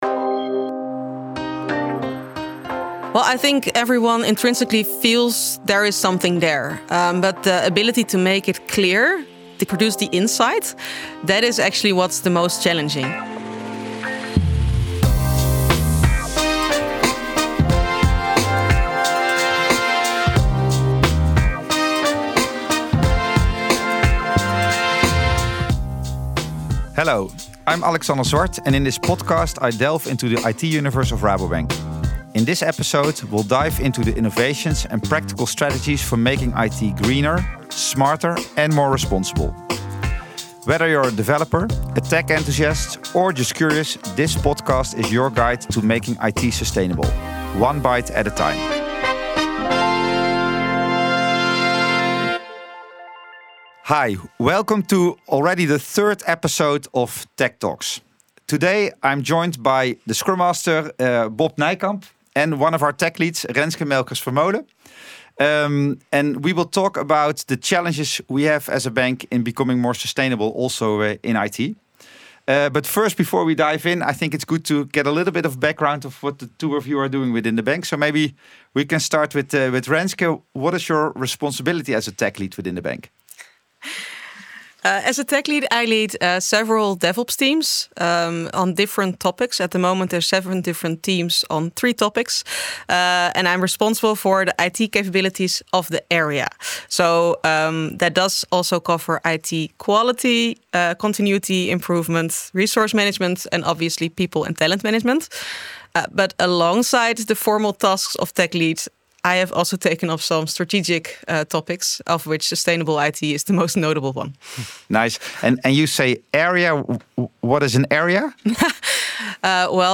Dit is ‘Werken bij Rabobank‘, waarin we in gesprek gaan met collega’s over hoe zij zichzelf, de bank en de wereld beter willen maken.